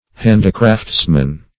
Search Result for " handicraftsman" : The Collaborative International Dictionary of English v.0.48: Handicraftsman \Hand"i*crafts`man\ (-kr[.a]fts`man), n.; pl.
handicraftsman.mp3